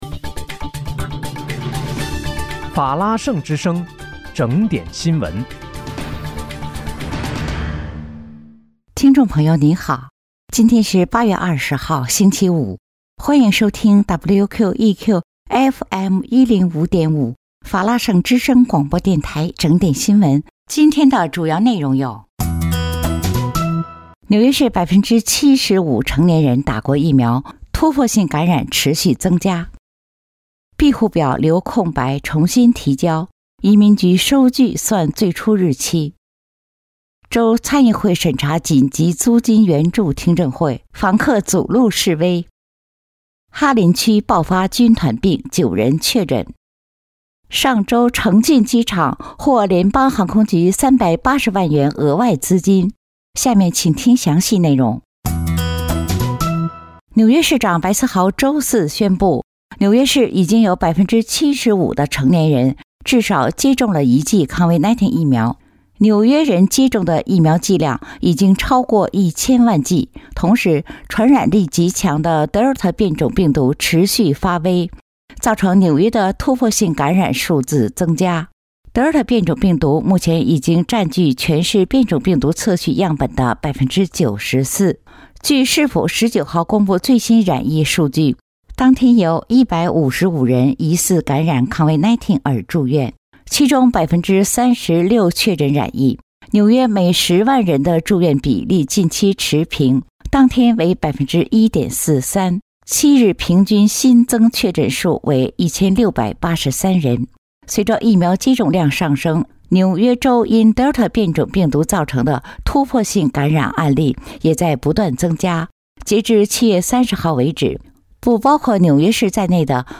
8月20日（星期五）纽约整点新闻
听众朋友您好！今天是8月20号，星期五，欢迎收听WQEQFM105.5法拉盛之声广播电台整点新闻。